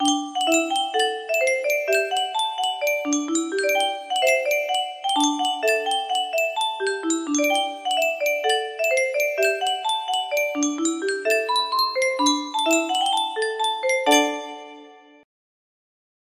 Clone of Yunsheng Music Box - Princeton Cannon Song Y556 music box melody